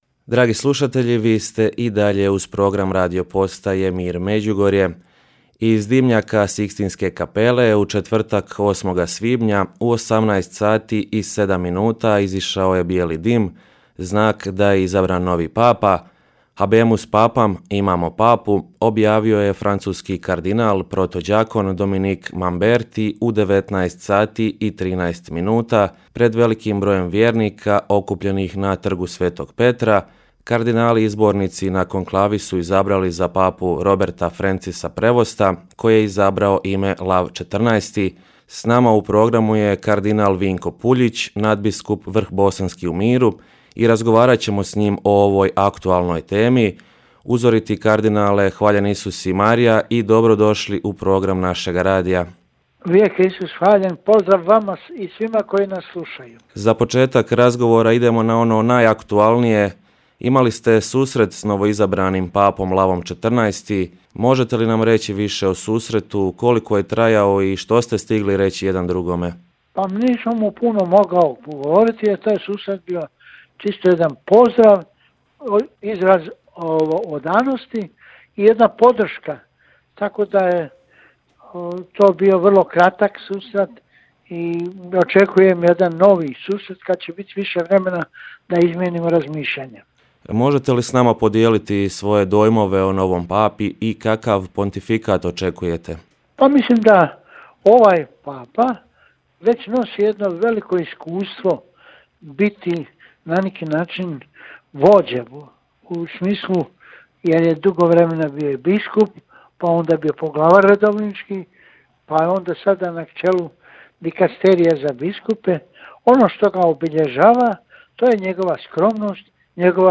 Gost u programu našeg radija bio je kardinal Vinko Puljić, nadbiskup vrhbosanski u miru. U početku razgovora govorio je o susretu s novoizabranim papom.